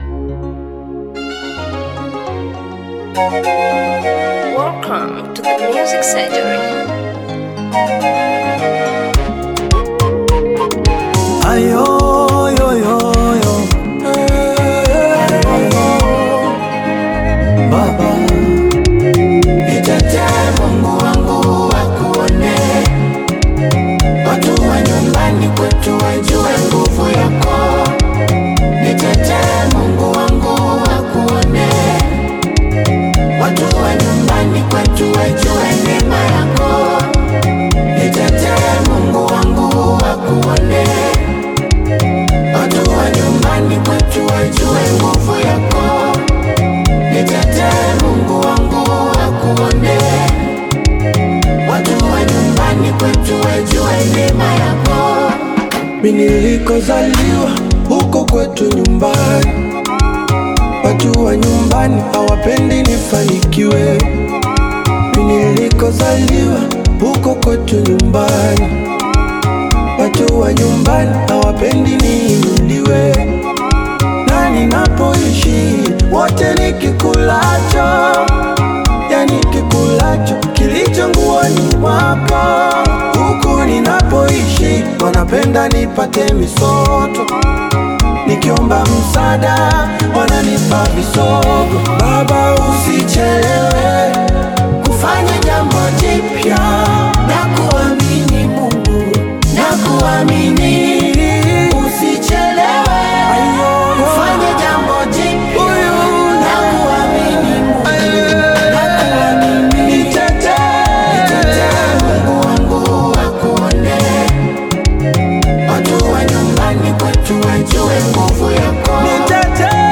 Gospel music track
Gospel song